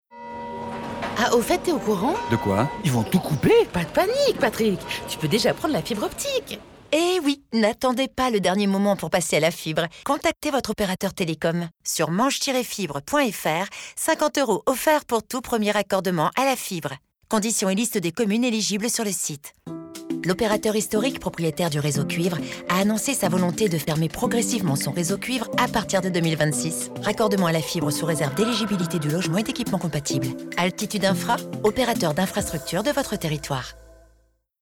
complice